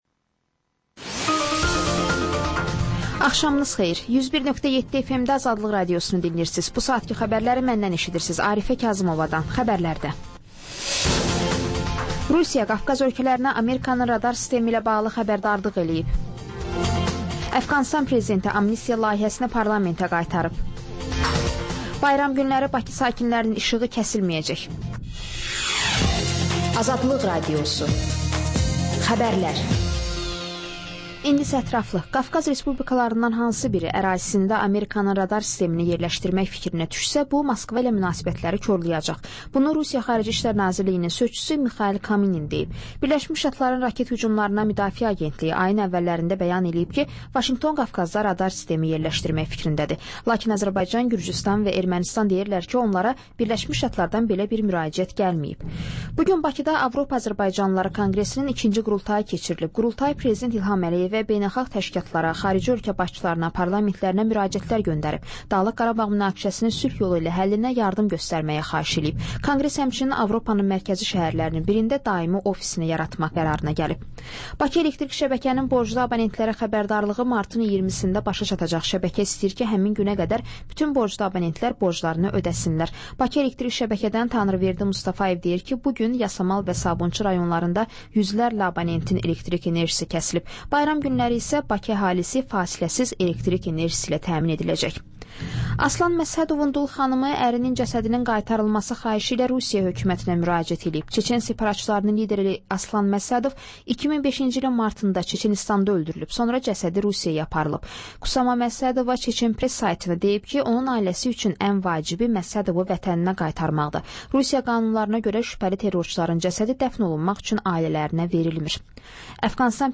Xəbərlər, reportajlar, müsahibələr. Panorama: Jurnalistlərlə həftənin xəbər adamı hadisələri müzakirə edir. Və: Qafqaz Qovşağı: Azərbaycan, Gürcüstan və Ermənistandan reportajlar.